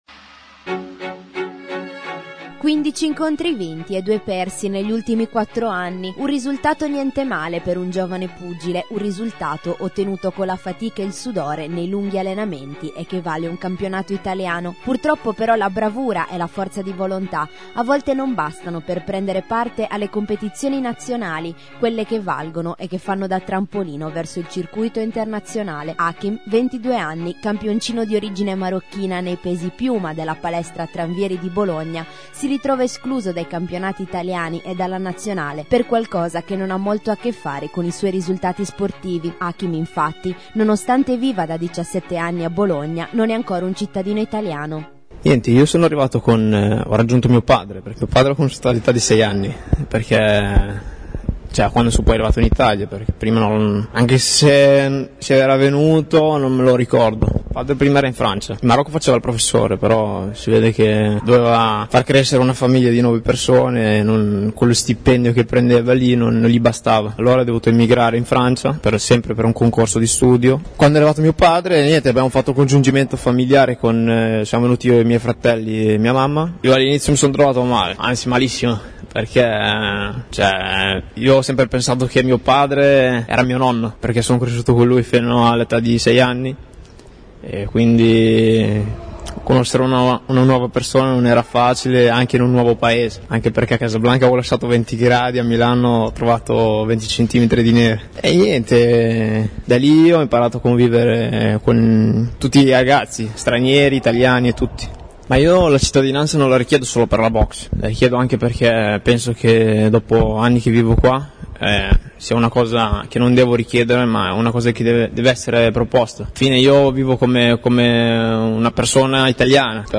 servizio